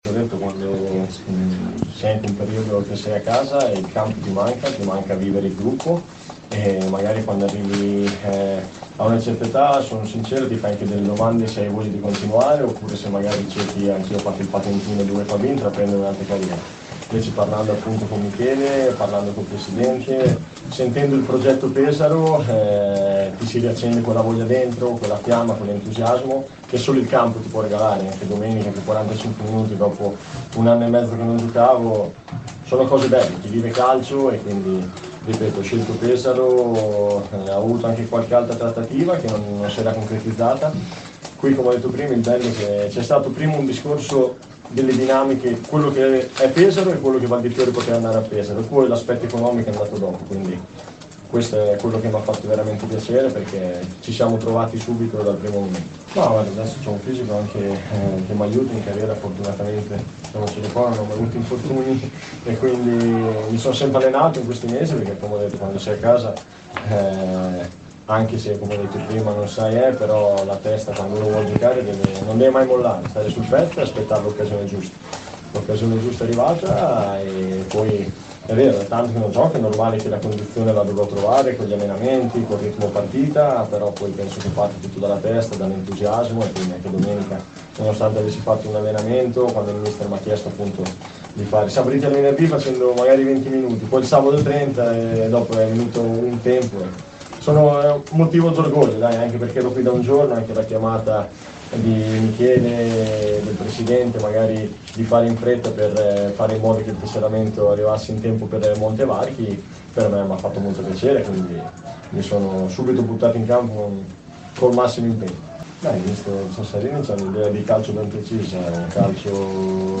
Ai nostri microfoni, le sue parole durante la conferenza stampa.